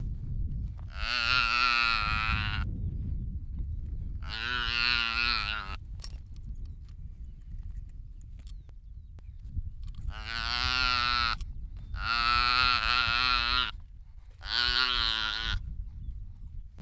Звук новорожденного сайгачонка